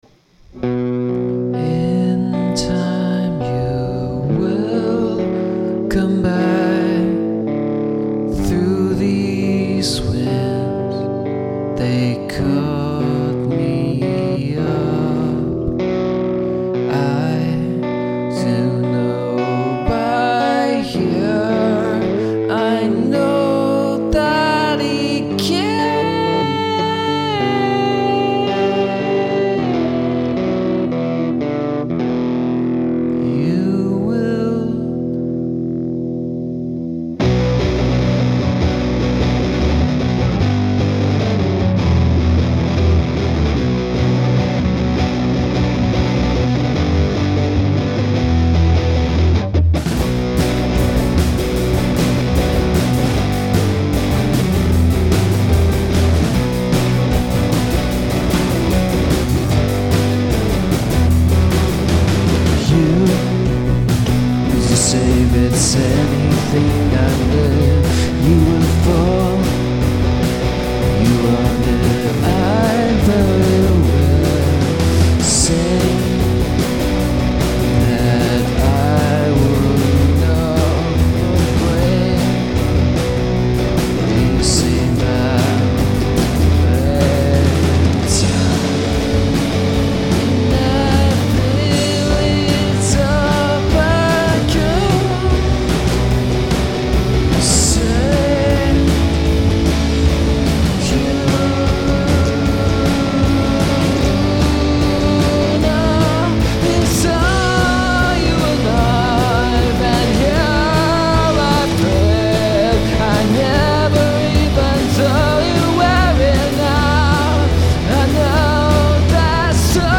I wanted to do a more rocking song than I've been doing lately, and I also wanted to have a song that started off with a slow acoustic(ish) intro before going into the rock.
2) I like the texture of the chorus and all the parts, but I didn't record enough backing track for the 2nd verse to work, which is why that sounds sort of random and mumbly (well, more so than usual, this song as usual has no planned words).
I could have just done it separately, but I wanted the fast part to sort of come in when it seemed "natural" to me.
It IS rockin'.